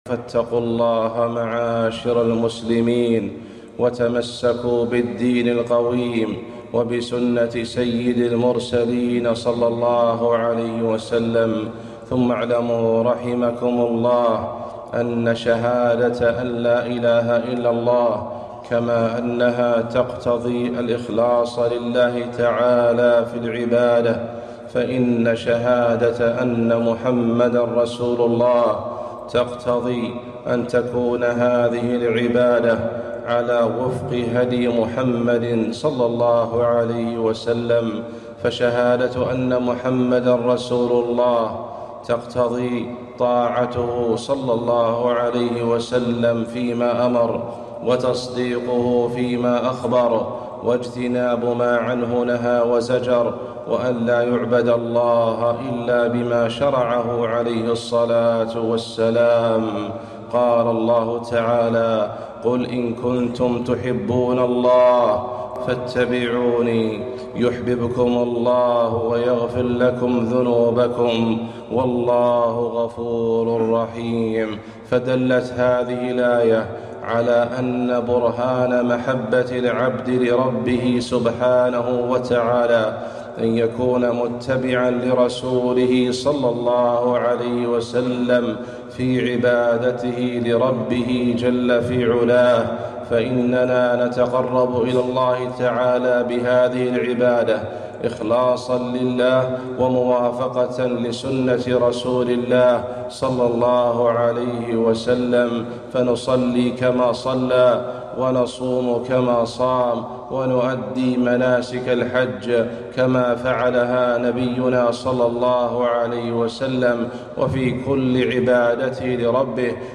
خطبة - محبة النبي صلى الله عليه وسلم بالاتباع وليس بالابتداع